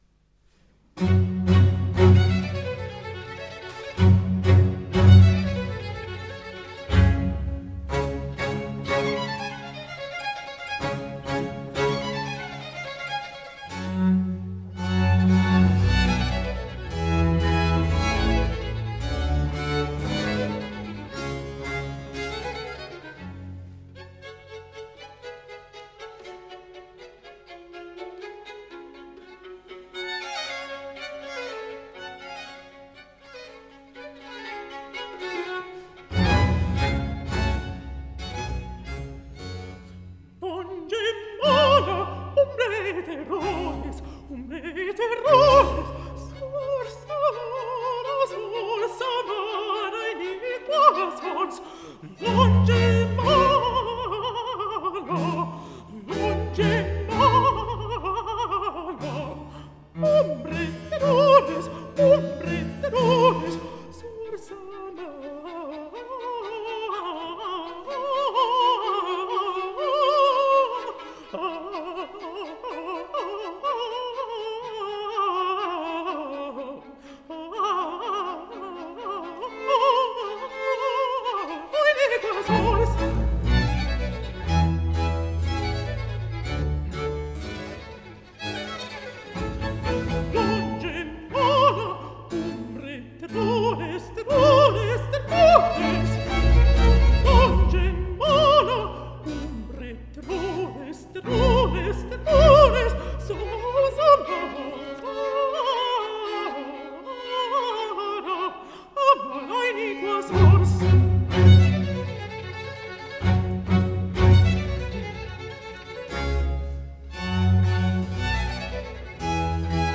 controtenore in registro di Contralto